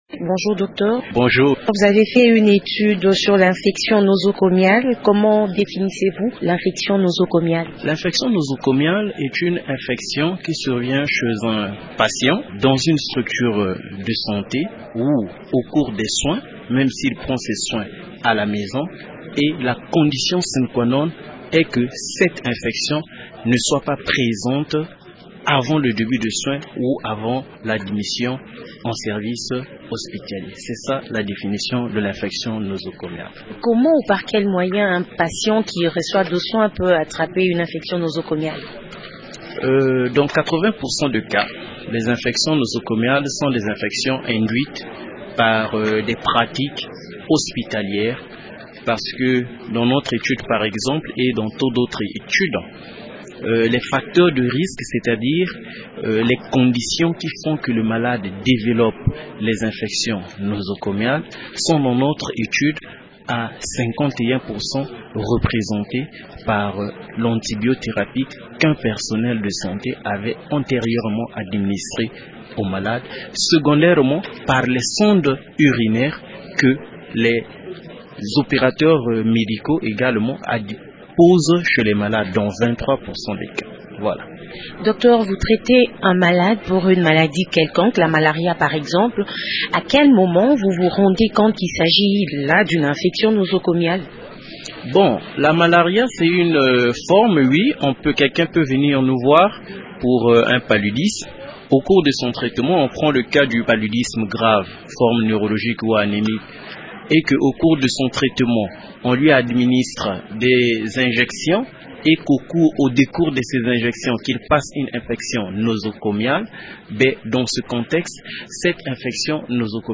Un spécialiste  des maladies infectieuses et tropicales a présenté les résultats de cette étude lors du sixième congrès de pathologie infectieuse et parasitaire qui s’est clôturé le week-end dernier à Kinshasa.